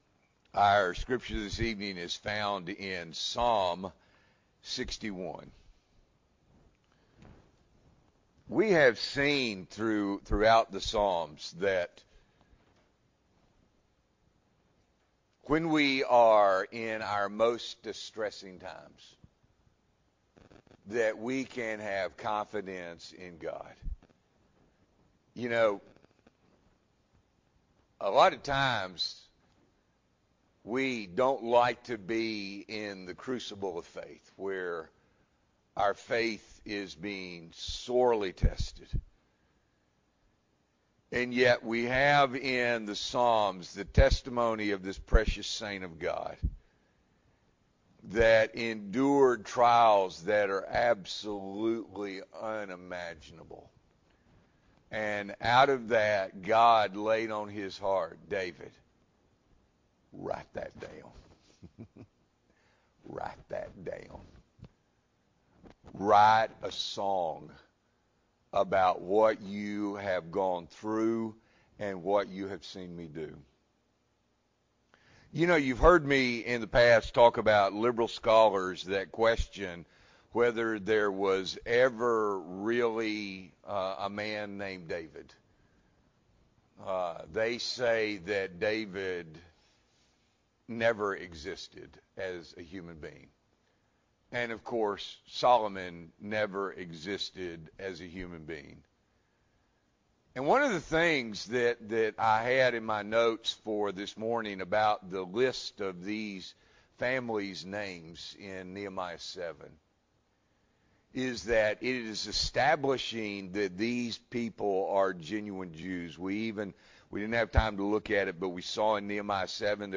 March 10, 2024 – Evening Worship